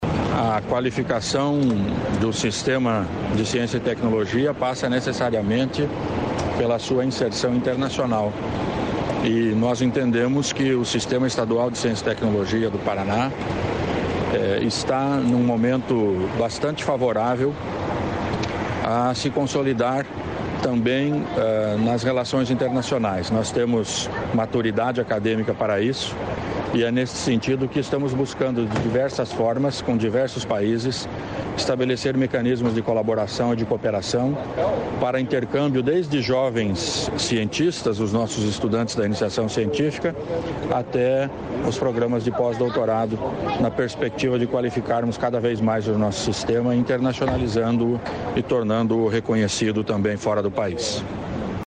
Sonora do secretário estadual da Ciência, Tecnologia e Ensino Superior, Aldo Bona, sobre parcerias com o Japão